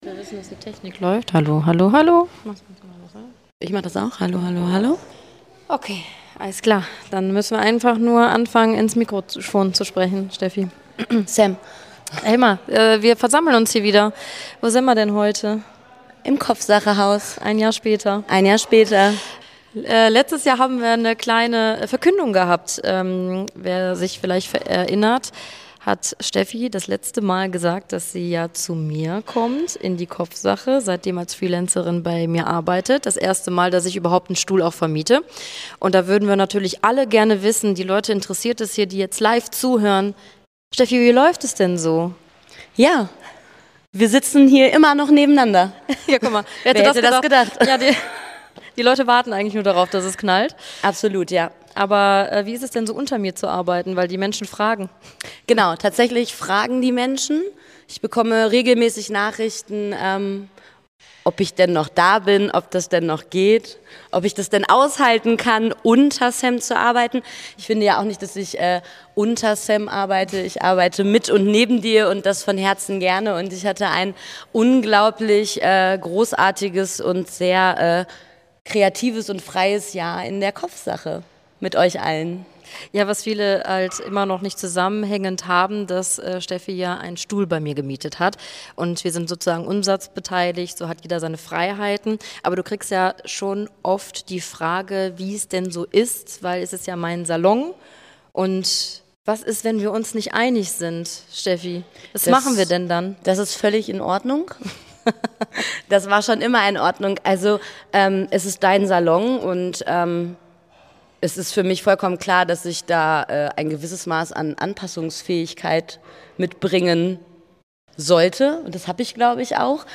All das und noch viel mehr erzählen sie live aus dem Kopfsache Haus 2024 – inklusive zusätzlicher Einblicke und F*ck-Ups von Kolleg , die ebenfalls zu Wort kamen.